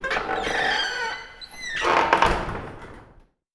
- Som usado para a porta 01
mixkit-creaking-public-toilet-door-203.wav